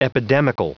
Vous êtes ici : Cours d'anglais > Outils | Audio/Vidéo > Lire un mot à haute voix > Lire le mot epidemical
Prononciation du mot : epidemical